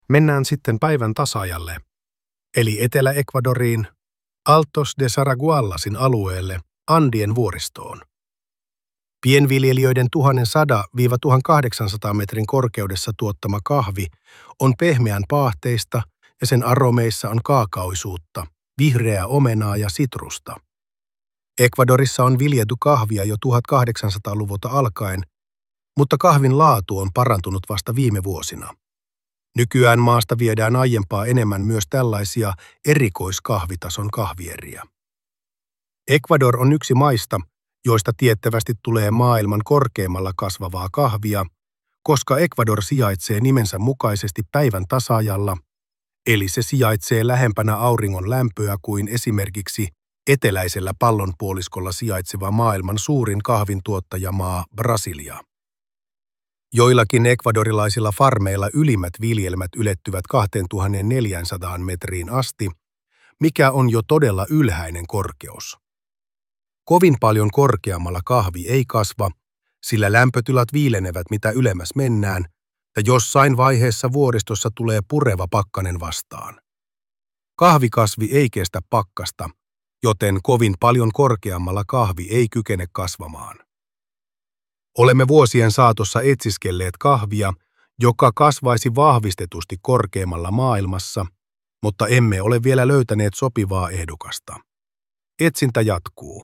Tarinan äänitiedosto on luotu tekoälyllä.